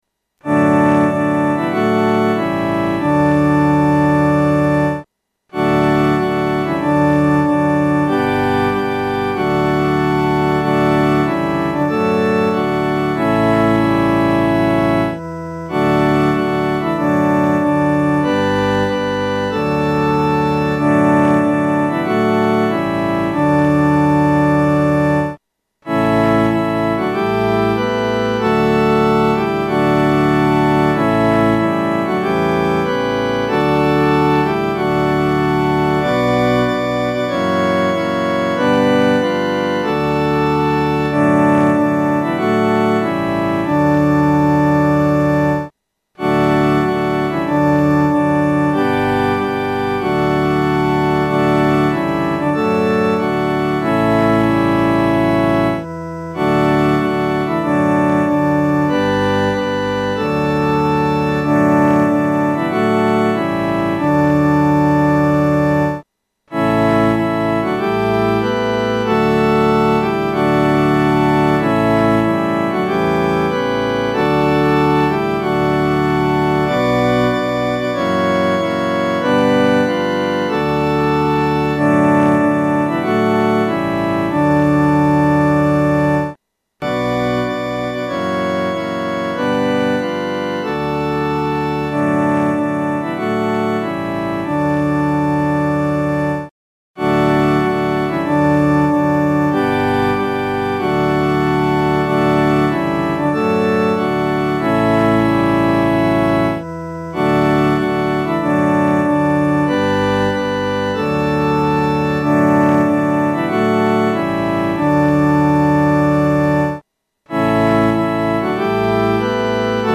伴奏
四声
且具有进行曲的风味。